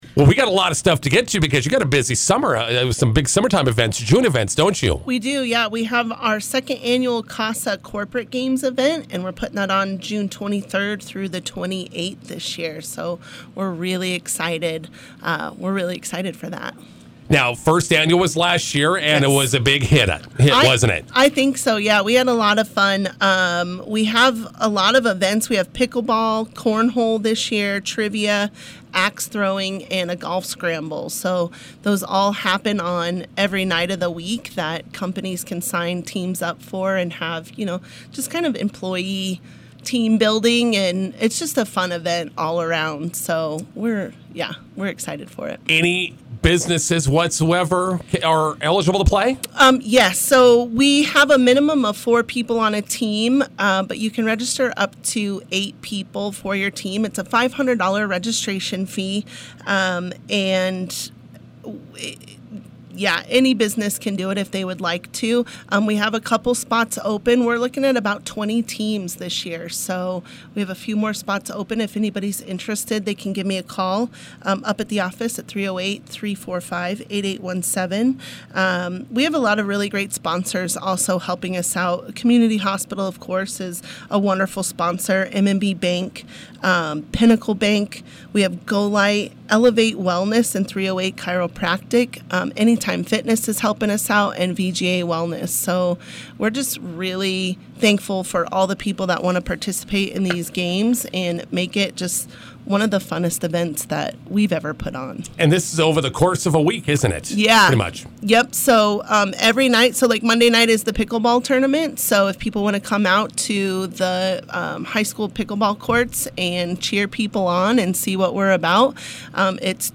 INTERVIEW: Prairie Plains CASA Corporate Games signup deadline is this Friday.